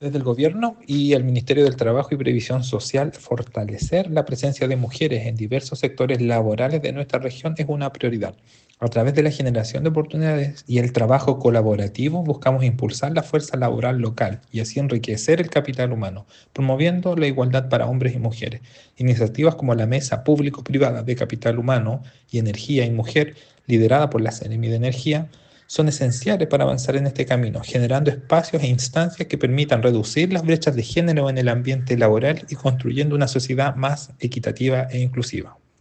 El Museo Interactivo de Osorno fue el lugar de encuentro para desarrollar la Segunda Mesa Regional de Energía Más Mujeres y Capital Humano, una iniciativa desarrollada en conjunto entre el Ministerio de Energía y la Agencia de Sostenibilidad Energética.